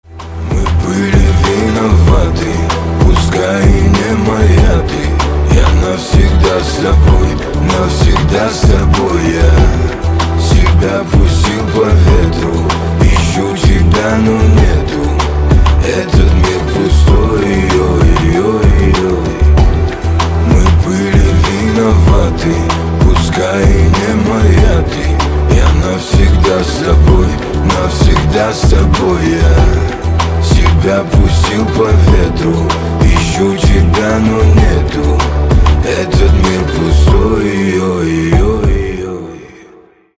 • Качество: 320, Stereo
мужской вокал
рэп
грустные
русский рэп
спокойные